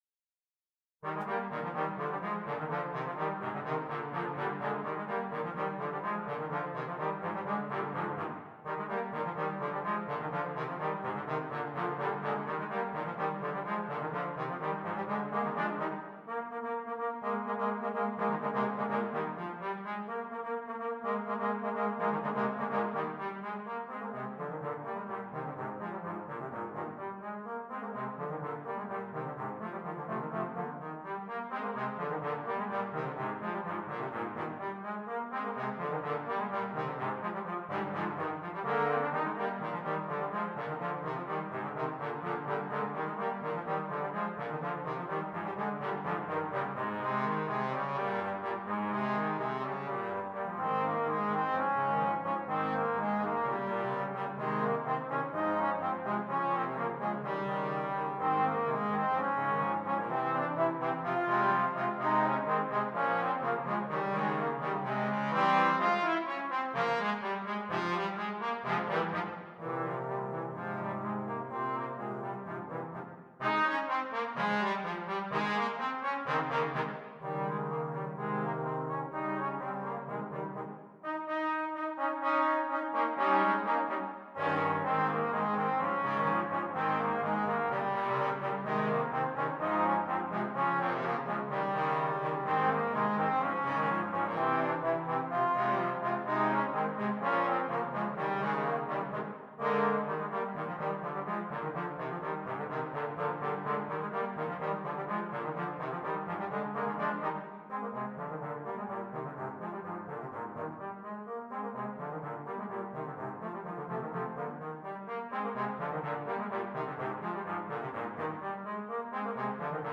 3 Trombones